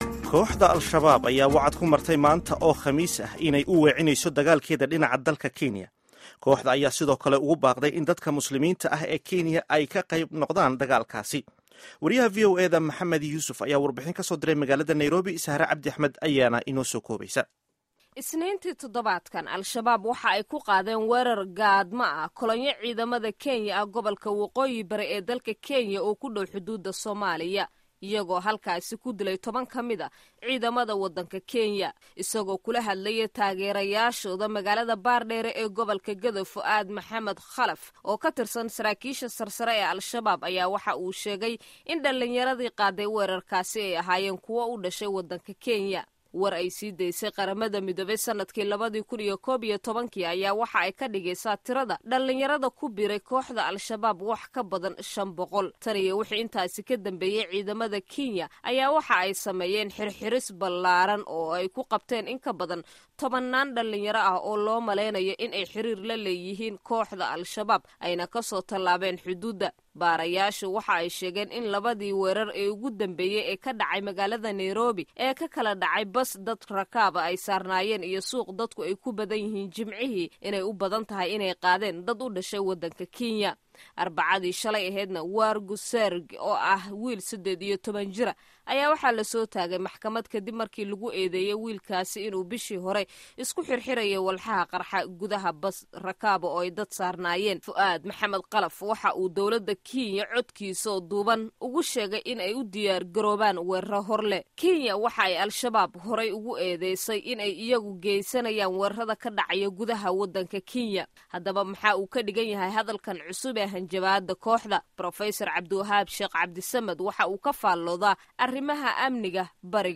Warbixinta Kenya iyo Shabab